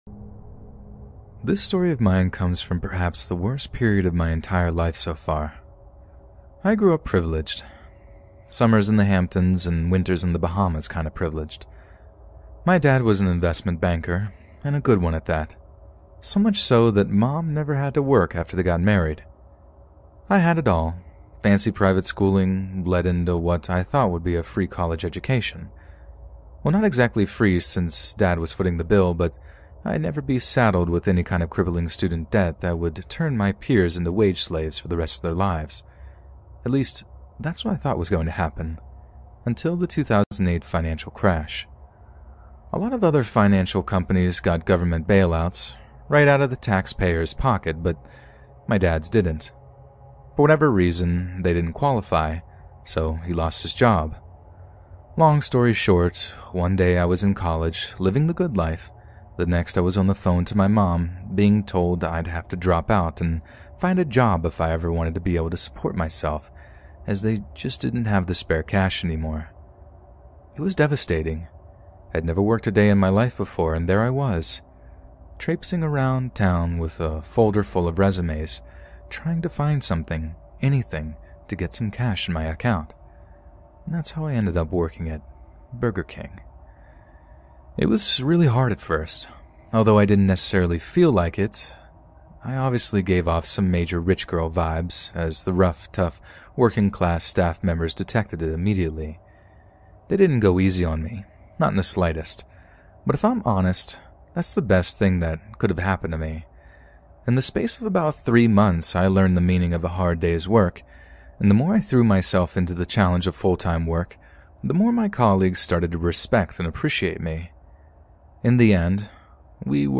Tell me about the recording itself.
Before the story begins, Scary Stories wants you to know something important: all advertisements are placed at the very beginning of each episode so nothing interrupts the experience once the darkness settles in.